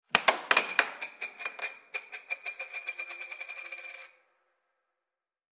Air Hockey; Puck Hits And Bounce On Table.